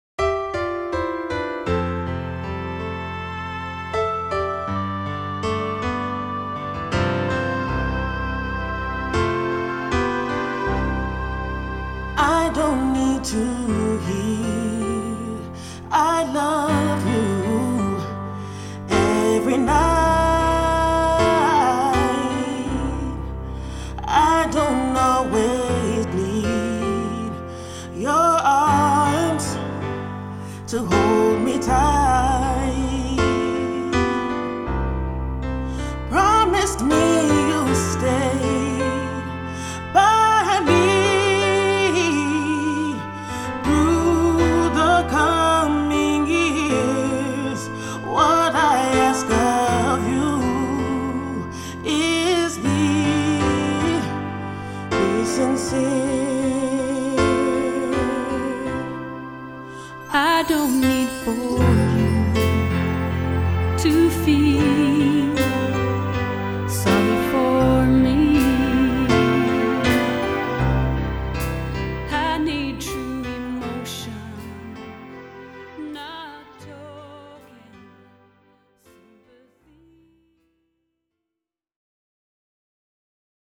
Here are some tracks from the studio that we hope you enjoy.